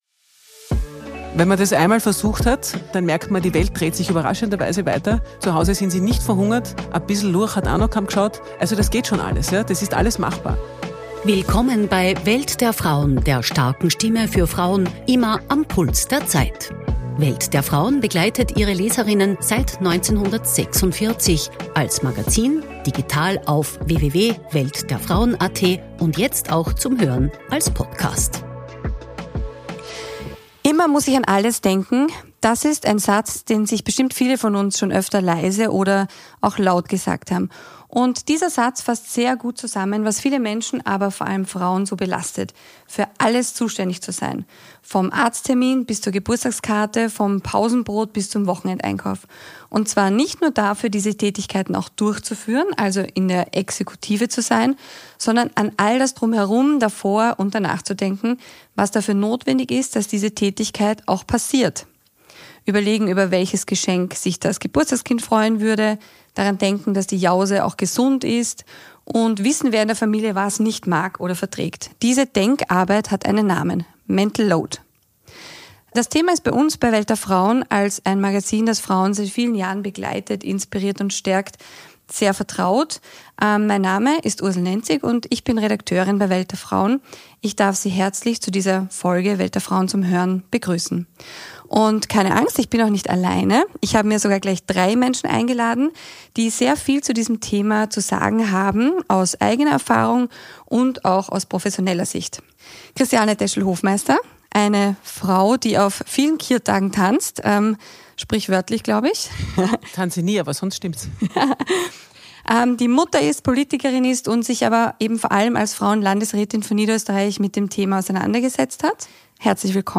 Eine Politikerin, eine Psychotherapeutin und ein Männerforscher zu Gast im Podcast